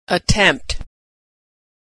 Voiceless /t/
When /t/ is stressed, it has a puff of air.
When /t/ is not stressed, it does not have a puff of air.